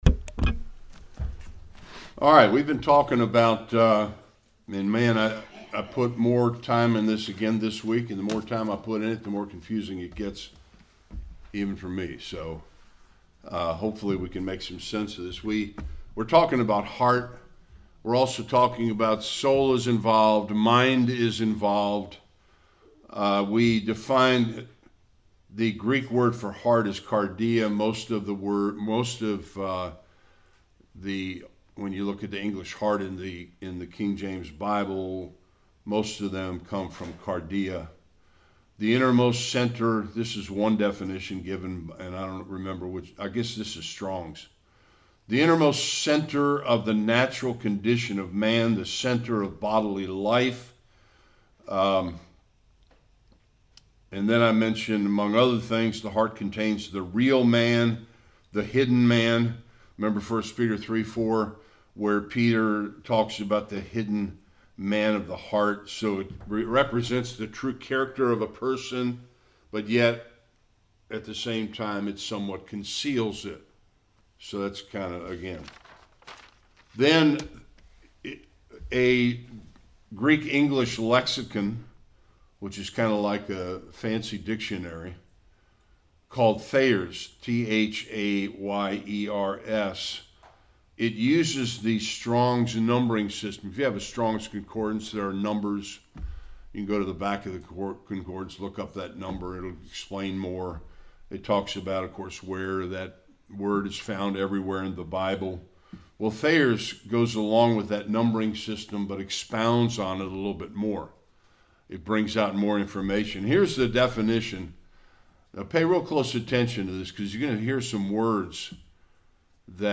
September 3, 2023 Heart, soul, mind; conclusion Passage: Various Passages Service Type: Sunday School Various ways heart, soul, and mind are used in the Old and New Testaments.